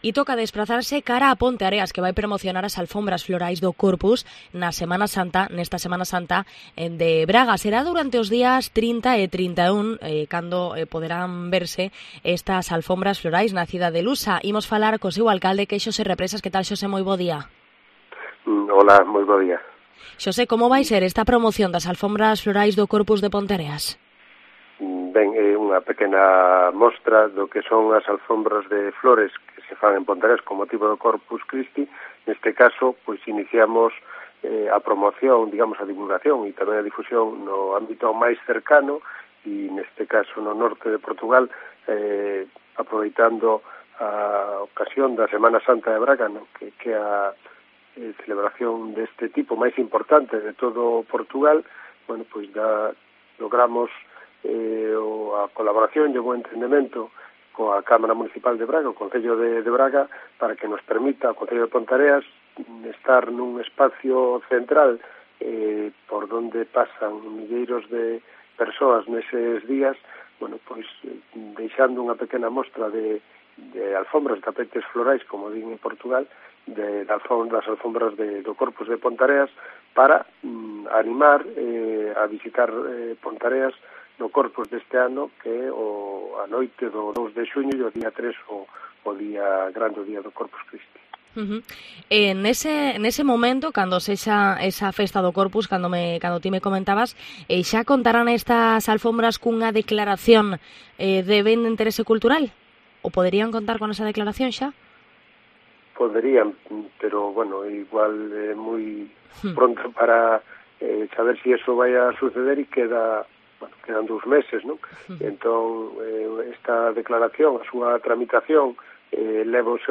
¡Puedes escuchar la entrevista completa al alcalde de Ponteareas, Xosé Represas, en COPE Vigo!